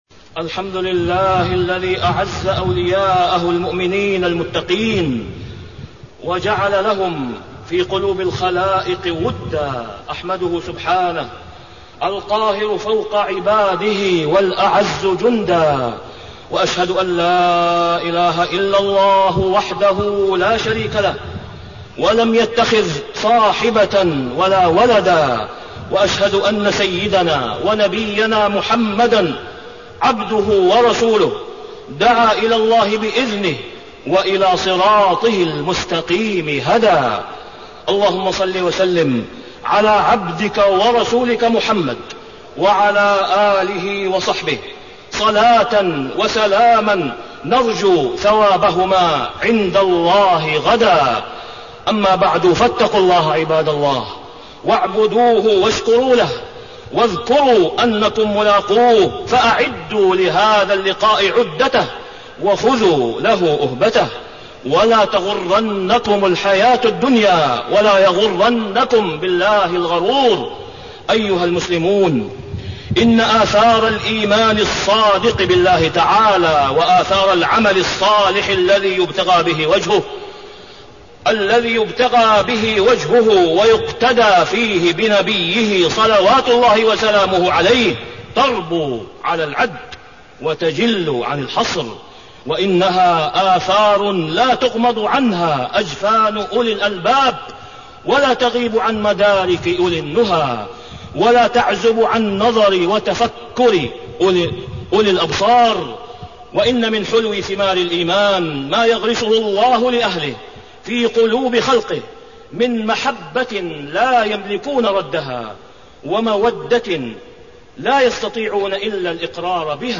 تاريخ النشر ٩ ربيع الأول ١٤٣٠ هـ المكان: المسجد الحرام الشيخ: فضيلة الشيخ د. أسامة بن عبدالله خياط فضيلة الشيخ د. أسامة بن عبدالله خياط الإيمان والتقوى وأثرهما في حب الله وحب الناس